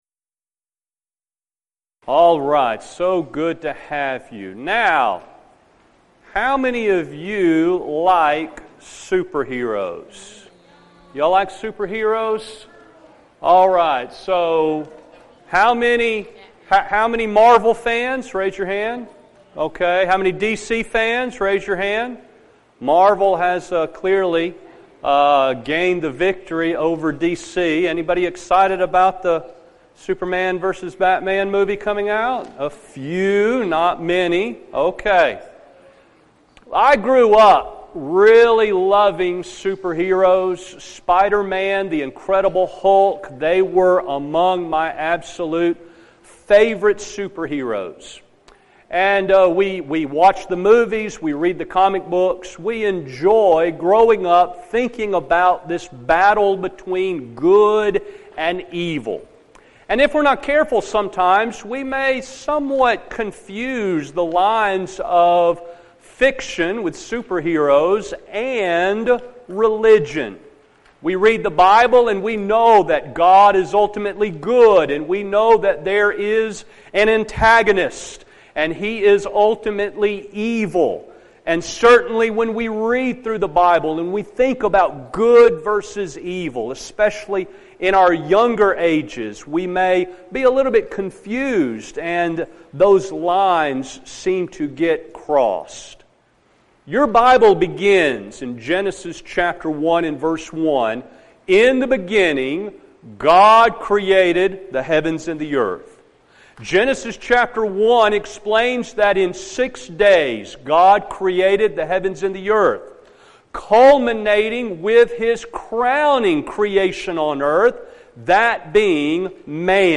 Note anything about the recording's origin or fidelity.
Event: 2015 Discipleship University